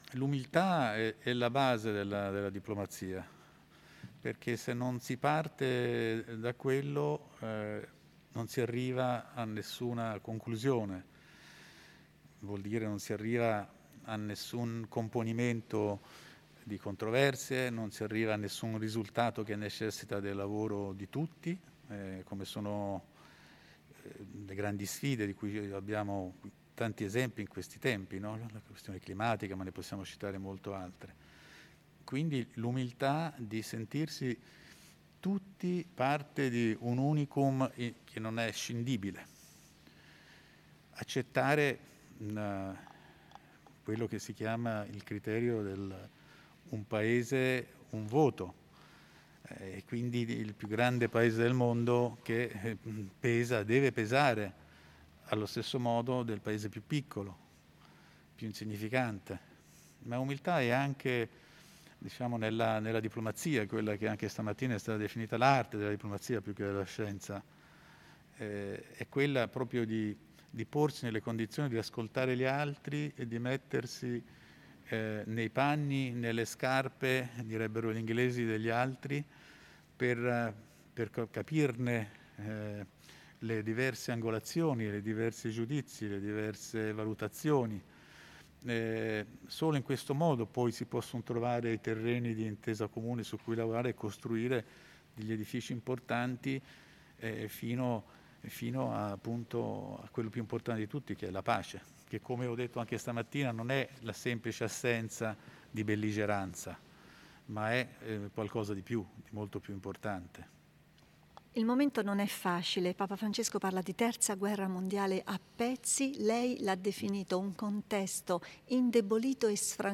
Nell’Aula Magna della Pontificia Università Lateranense si è svolta questa mattina la cerimonia del conferimento del titolo di “Magister in cooperazione internazionale” all’ambasciatore dell’Italia presso la Santa Sede Pietro Sebastiani, che è stato direttore generale della Cooperazione al Ministero degli Esteri italiano.
Sull’importanza dell’umiltà nell’impegno diplomatico si sofferma l’ambasciatore Pietro Sebastiani: